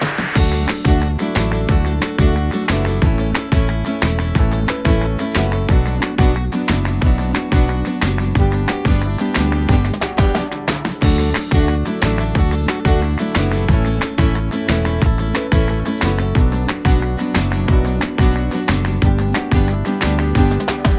Produkt zawiera utwór w wersji instrumentalnej oraz tekst.